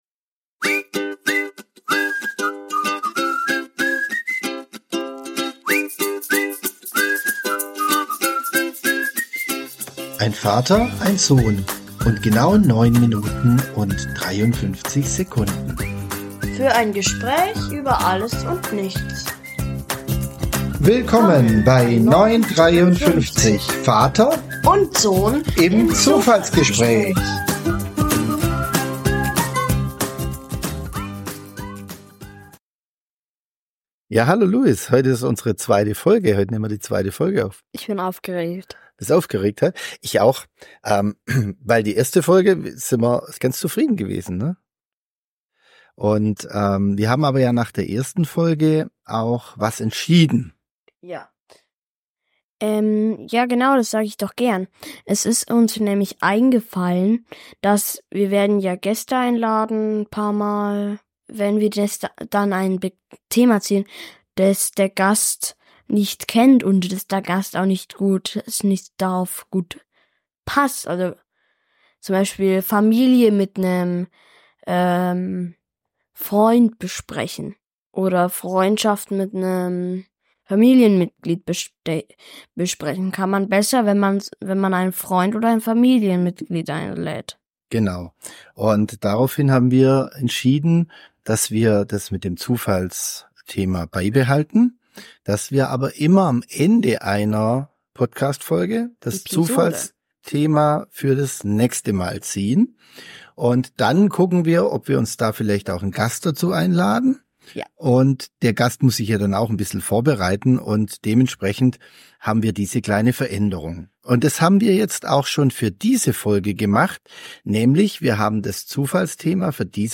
Gemeinsam vergleichen Vater und Sohn ihre Erfahrungen: von Lieblingsfächern über Hausaufgaben bis hin zu Schulgeschichten. Wir diskutieren, wie das Lernen sich verändert hat.